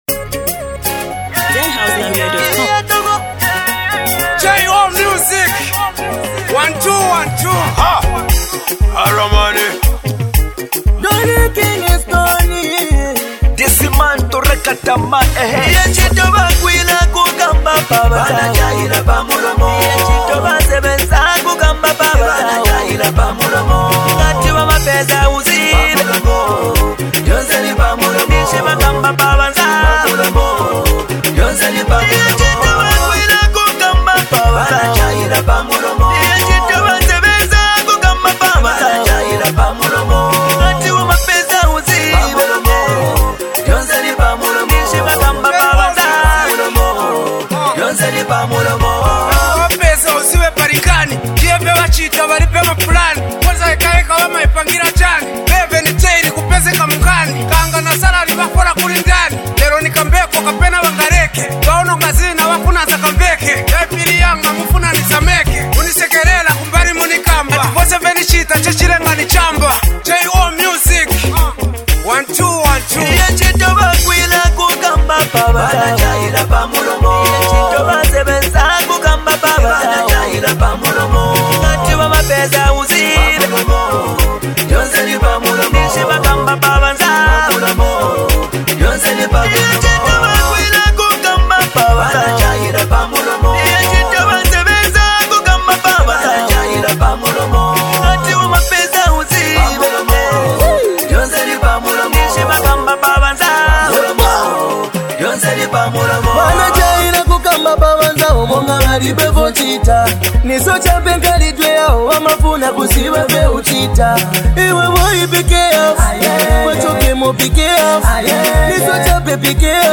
raw energy and street vibes
Hard verses, catchy hooks, and a beat that knocks heavy.